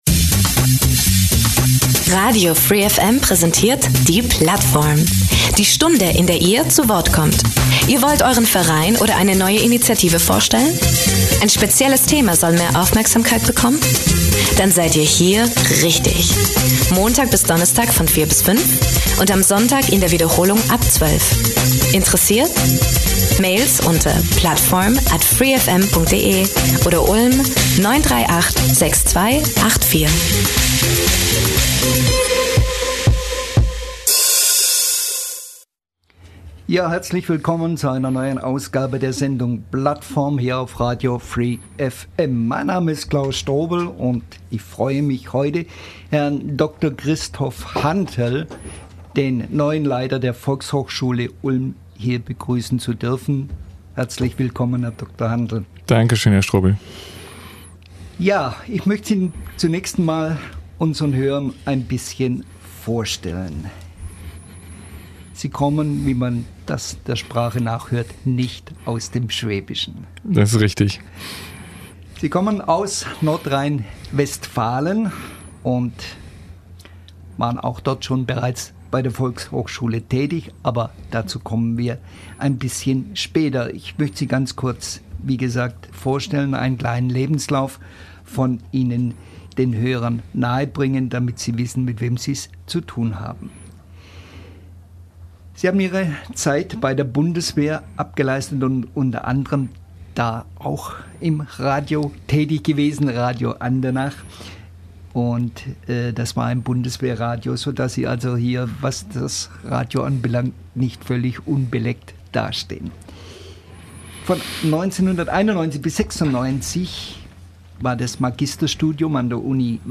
Gespräch mit dem neuen vhs-Leiter